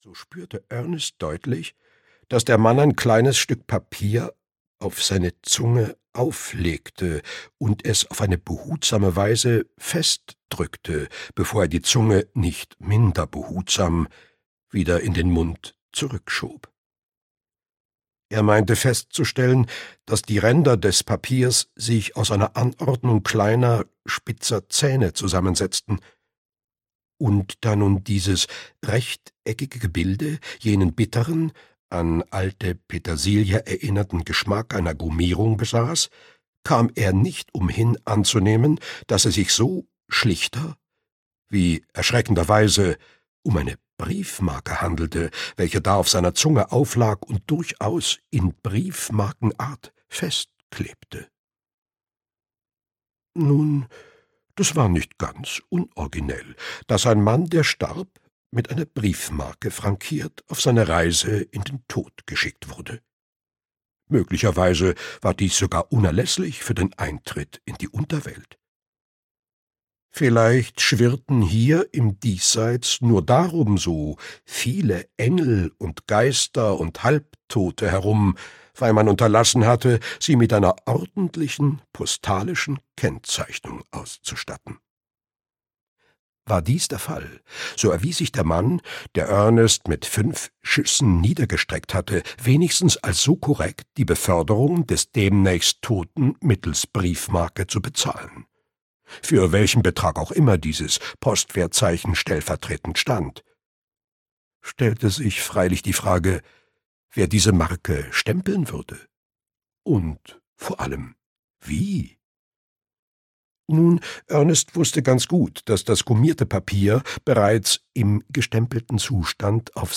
Batmans Schönheit (Markus-Cheng-Reihe 4) - Heinrich Steinfest - Hörbuch